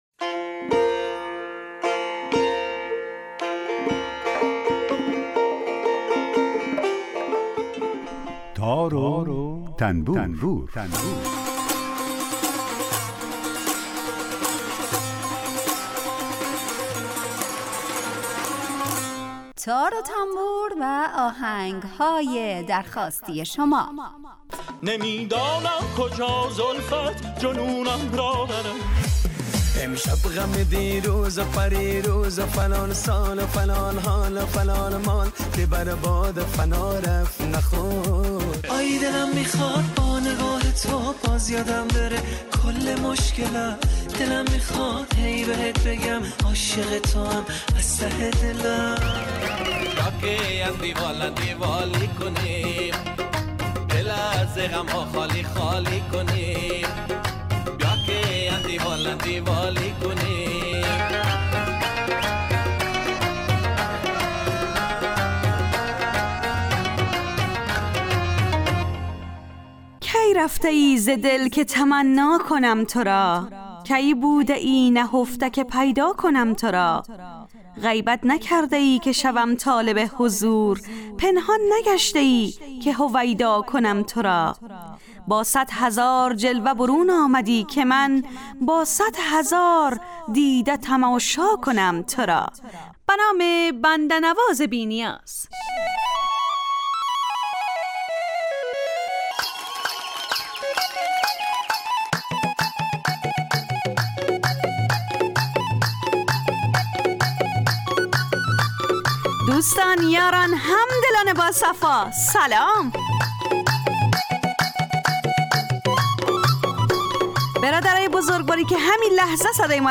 برنامه ای با آهنگ های درخواستی شنونده ها
یک قطعه بی کلام درباره همون ساز هم نشر میکنیم